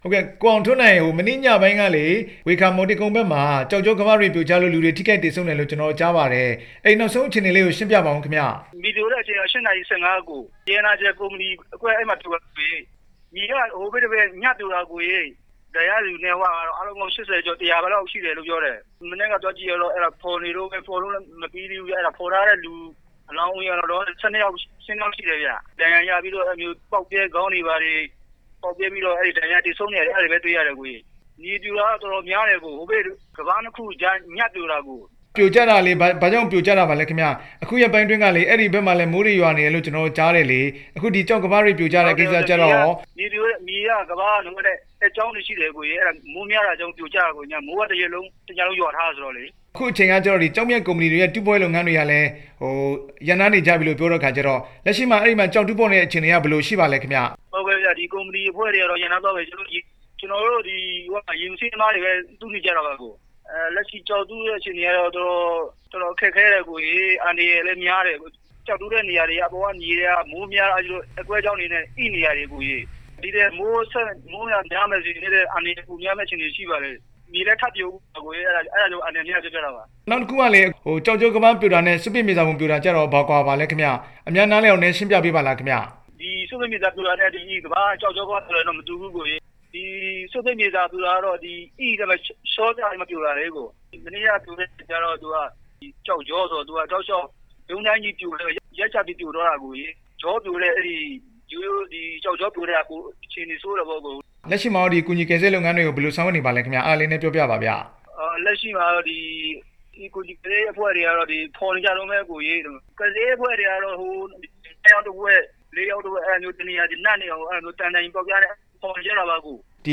ဖားကန့်မြေပြိုမှု မေးမြန်းချက်